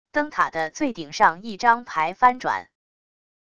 灯塔的最顶上一张牌翻转wav音频